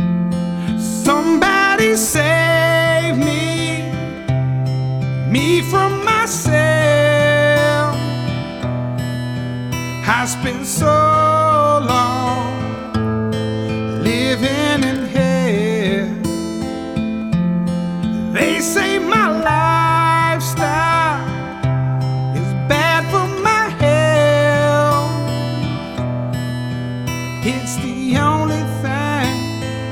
• Rap